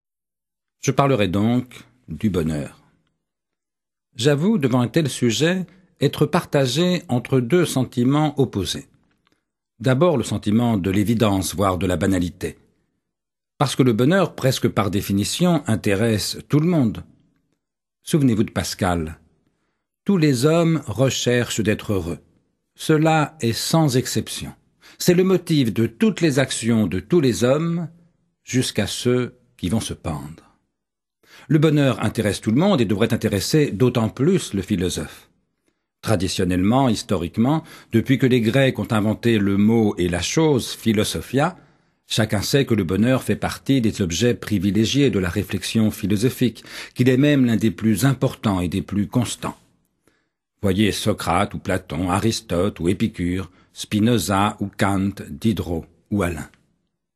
Lire un extrait - Le bonheur, désespérément de André Comte-Sponville